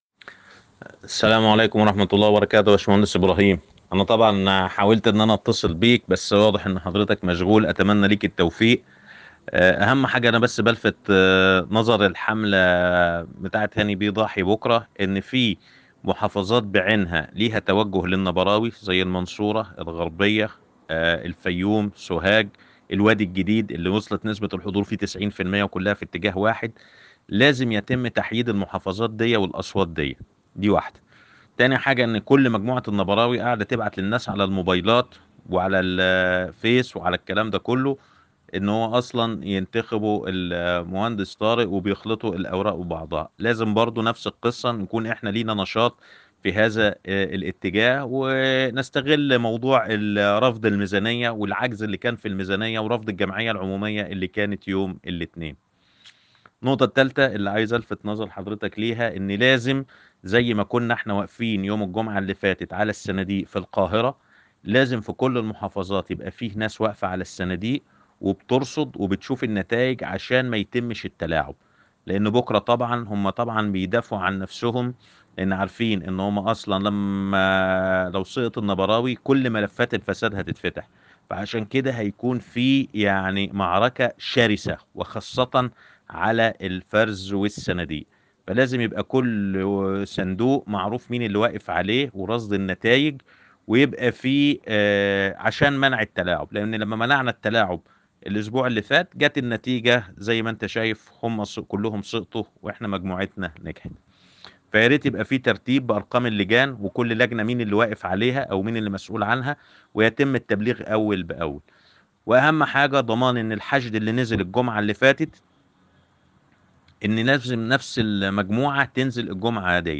رسالة صوتية لجموع المهندسين بتوخي الحذر من ألاعيب المنافس